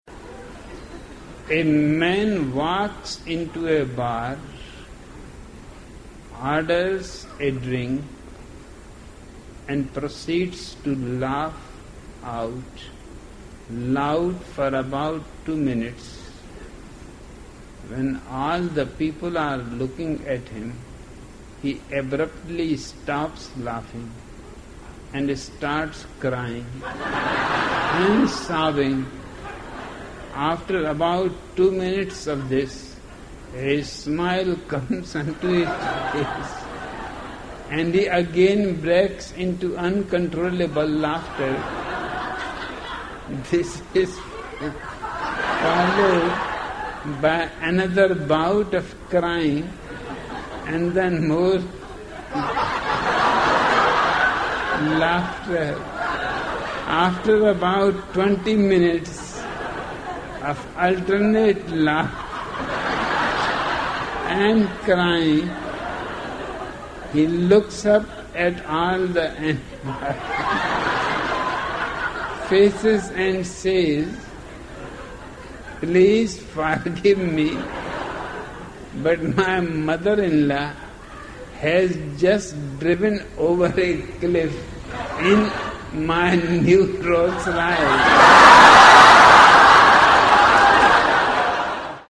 Audio file of Osho telling a joke.
Osho laughing during discourse